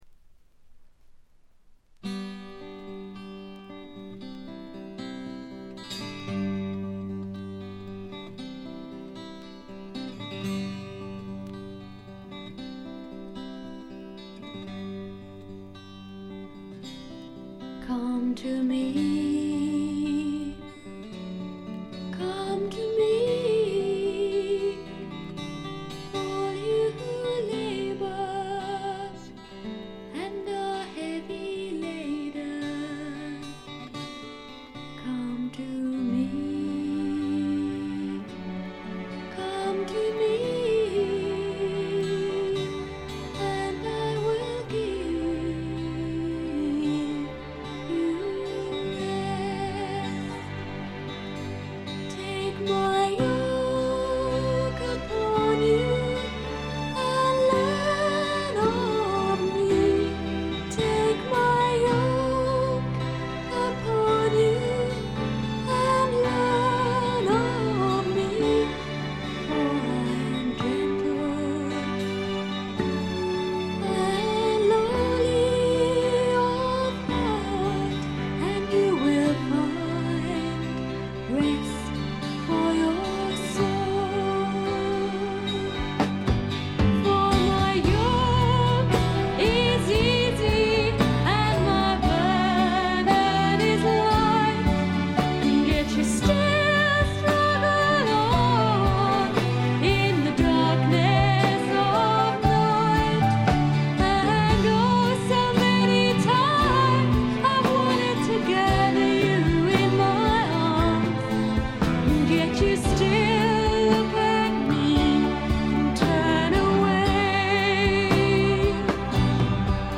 部分試聴ですが静音部での軽微なチリプチ程度。
古くからクリスチャン・ミュージック系英国フィメールフォークの名盤として有名な作品ですね。
試聴曲は現品からの取り込み音源です。